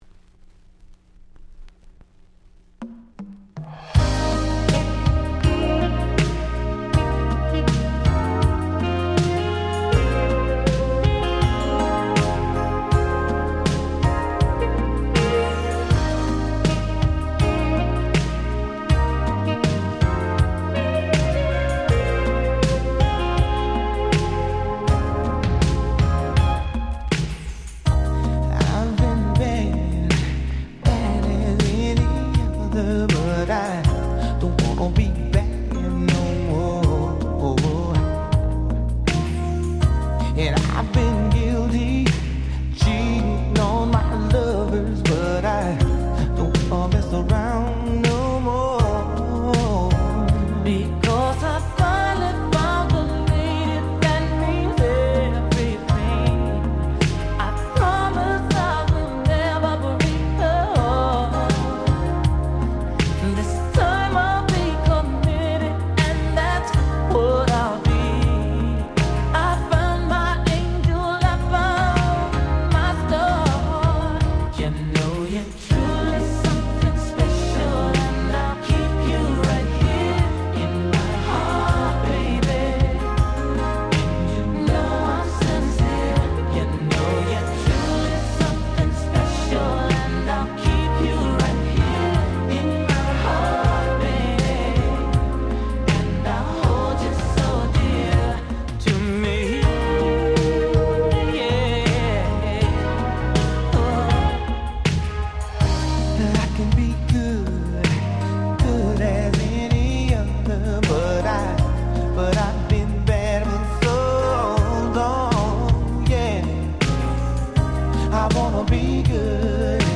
テーマは「グラウンドビート風味のPopsヒット」と言った所でしょうか。